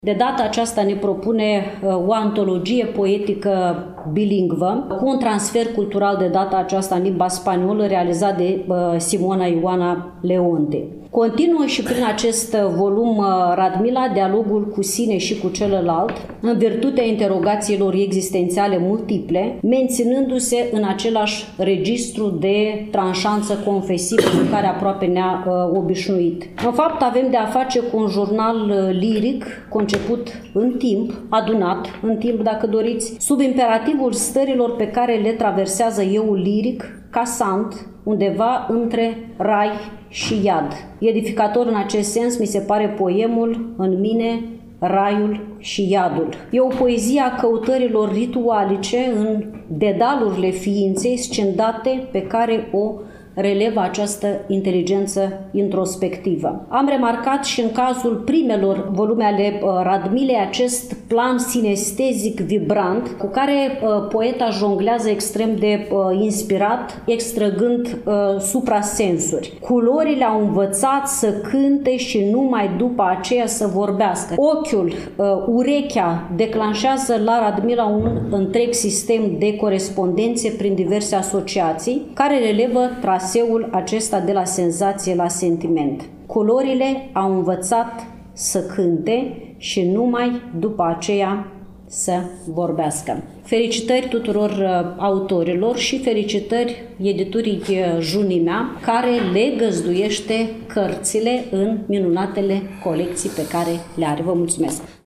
Cărțile au fost prezentate, la Iași, în ziua de joi, 21 noiembrie 2024, începând cu ora 14, în incinta sediului Editurii Junimea din Parcul Copou